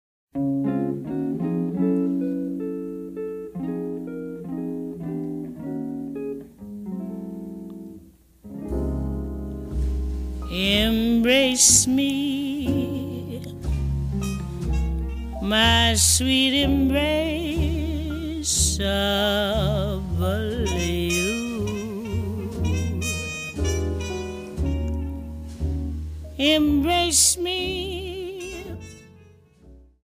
CD2の(12)-(20)はボーナス･トラックで、1957年7月、ニューポート･ジャズ祭でのステージの模様を収録。